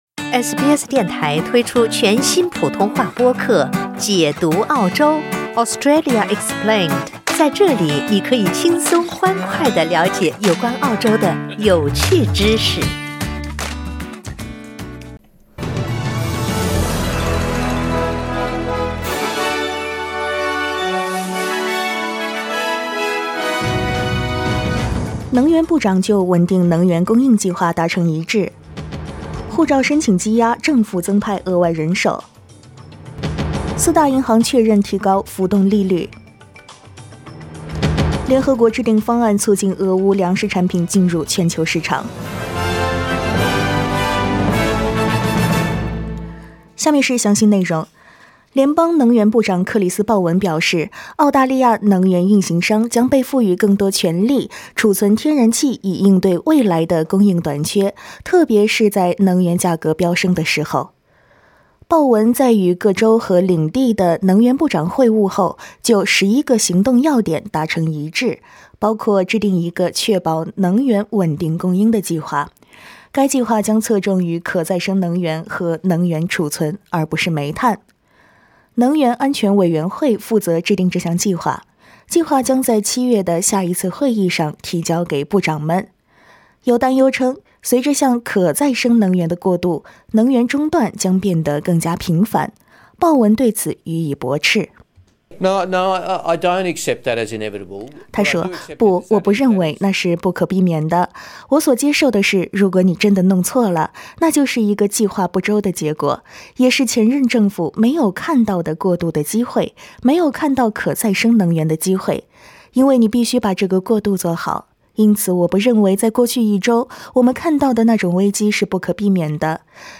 SBS早新闻（6月9日）
请点击收听SBS普通话为您带来的最新新闻内容。